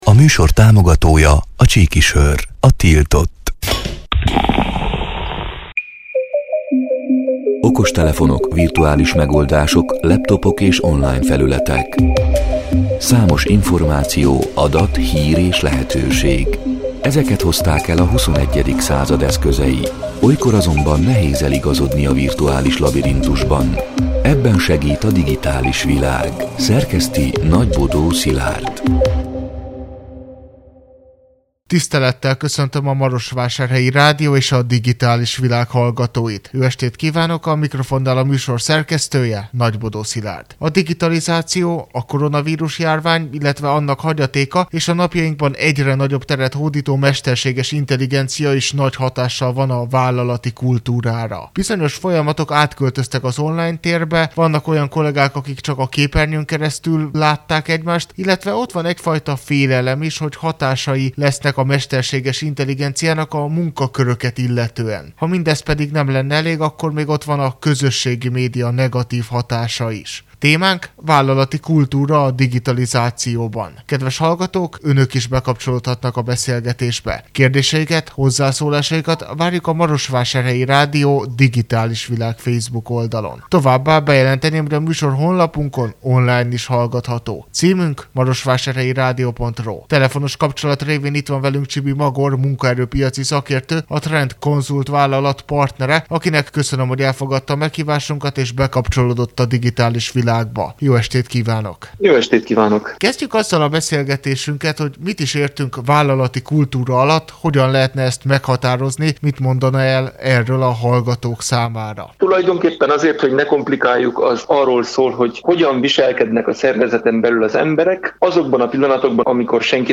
A Marosvásárhelyi Rádió Digitális Világ (elhangzott: 2025. február 25-én, kedden este nyolc órától) c. műsorának hanganyaga: